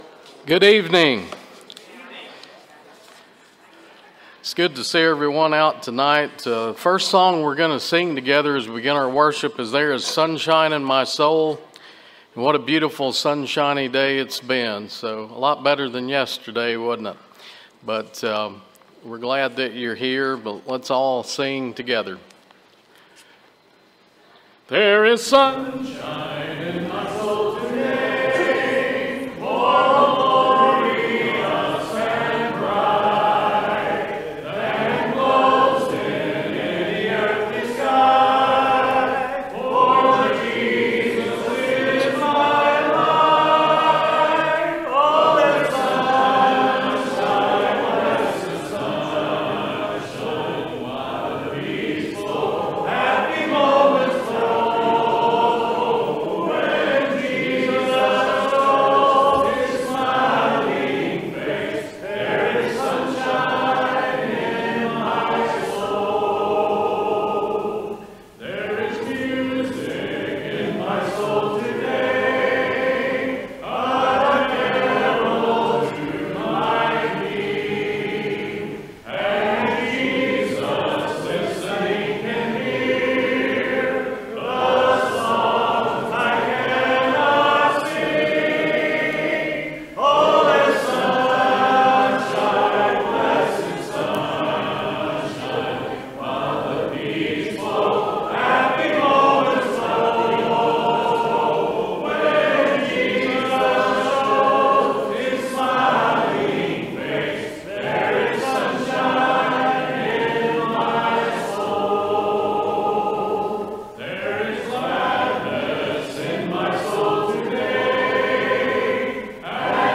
Proverbs 9:10, English Standard Version Series: Sunday PM Service